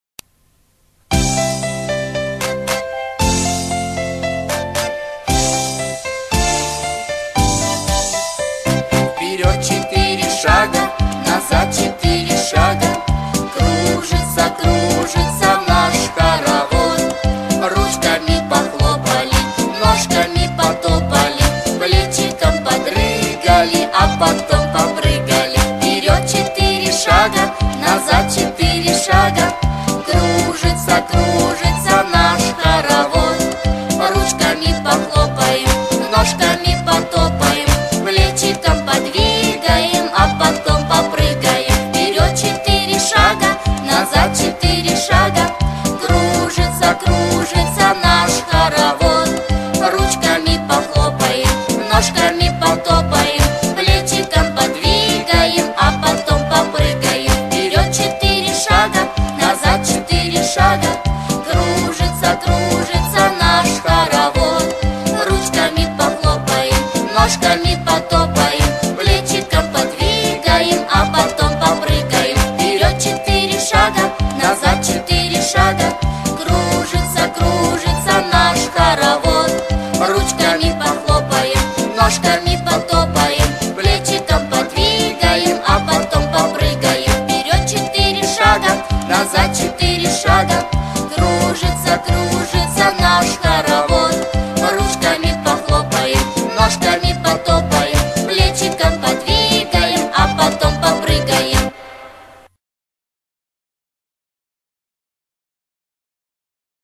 Интересная музыкальная игра, все действия выполняются по тексту песни; с каждым куплетом скорость выполнения движений увеличивается, детям нравится.